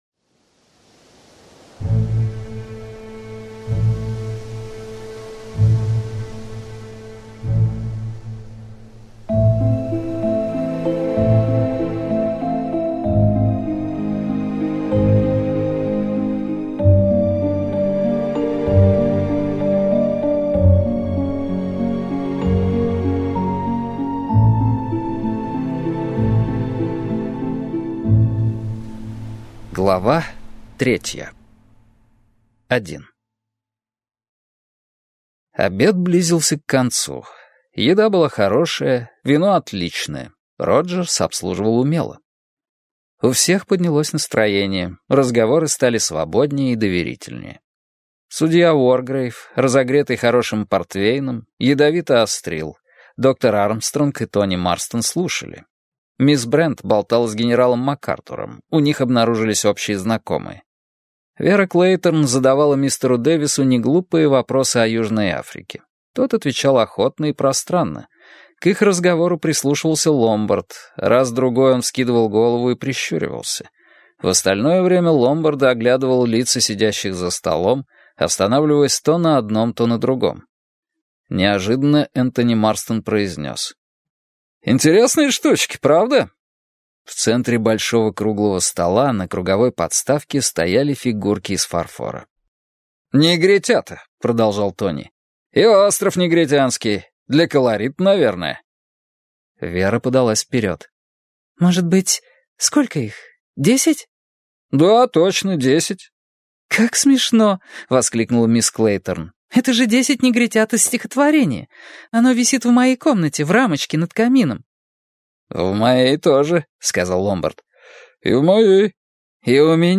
Аудиокнига Десять негритят - купить, скачать и слушать онлайн | КнигоПоиск